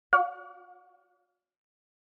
Звуки банковской карты
Звук при бесконтактной оплате телефоном (Apple Pay не удалось)